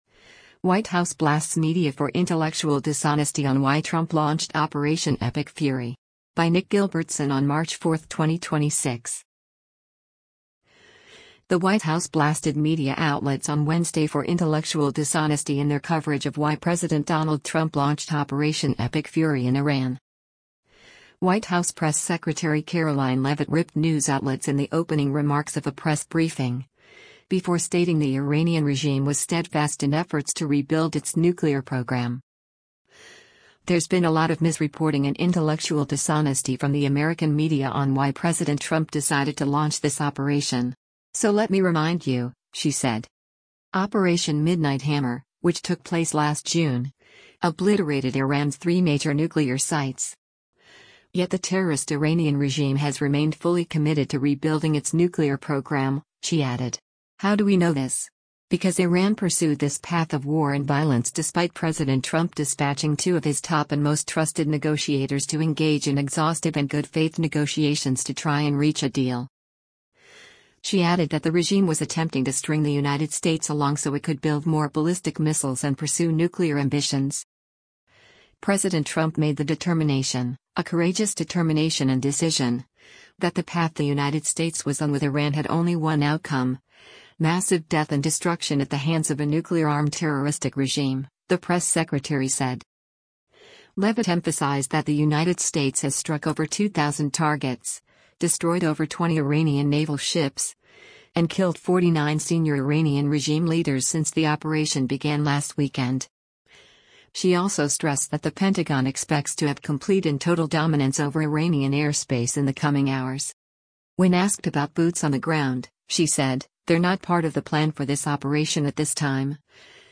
White House press secretary Karoline Leavitt ripped news outlets in the opening remarks of a press briefing, before stating the Iranian regime was steadfast in efforts to rebuild its nuclear program.